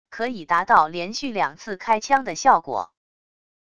可以达到连续两次开枪的效果wav音频